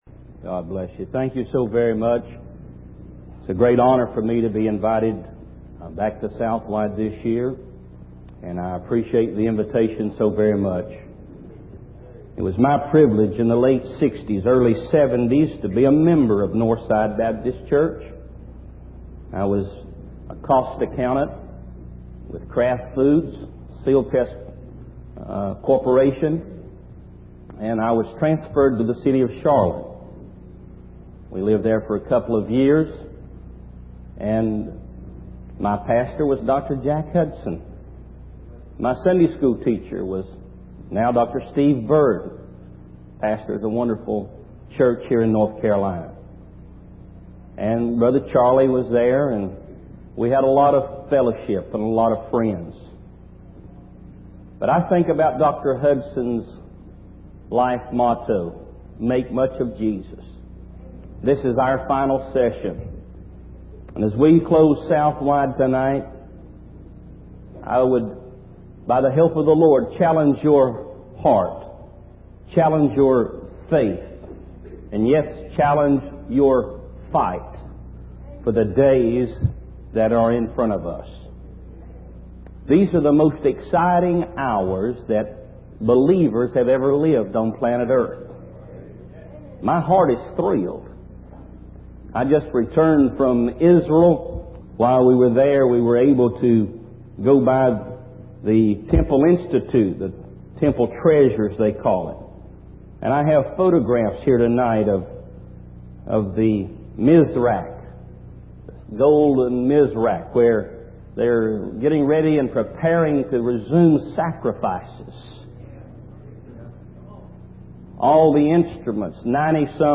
In this sermon, the speaker expresses his deep affection for his friends and heroes in the audience, but acknowledges that they cannot provide what he truly needs. He encourages the audience to focus on Jesus and his promises instead of being consumed by the storms of life. The speaker emphasizes the power of Jesus and his ability to give his life for sinners, conquer death, and even invade hell.